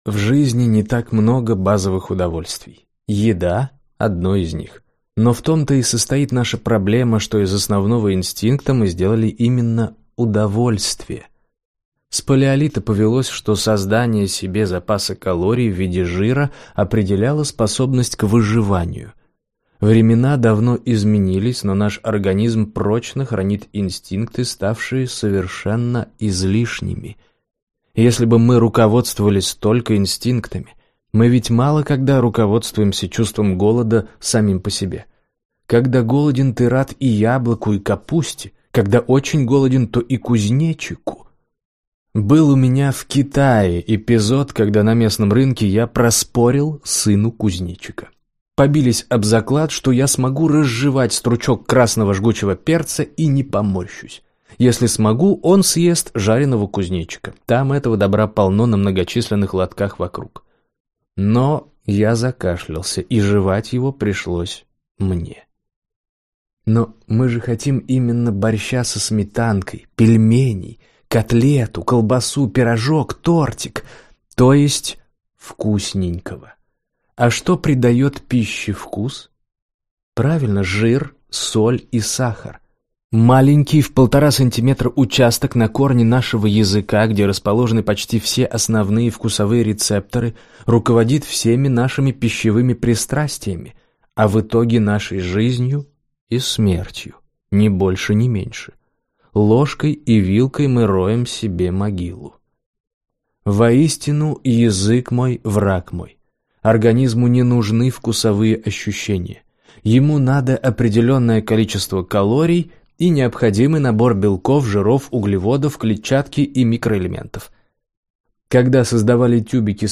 Аудиокнига Врача вызывали? Ответы на самые важные вопросы о здоровье, красоте и долголетии | Библиотека аудиокниг